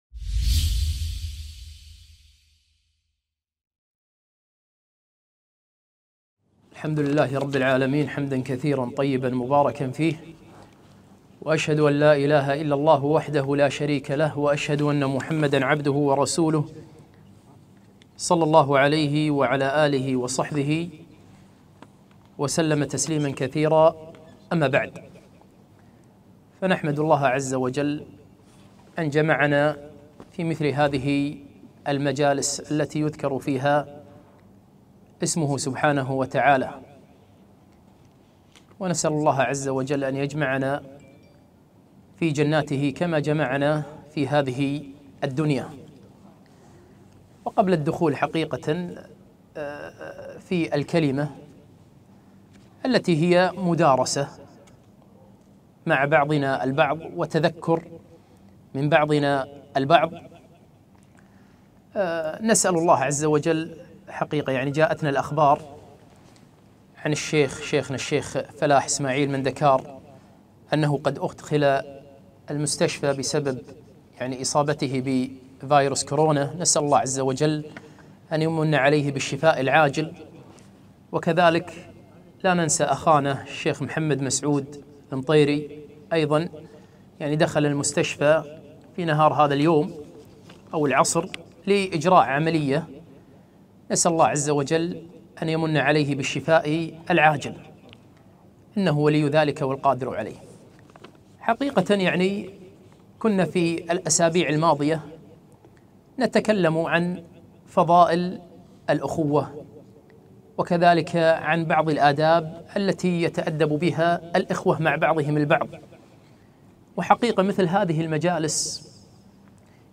محاضرة - آداب نحتاجها في مجالسنا